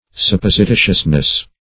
Sup*pos`i*ti"tious*ness, n.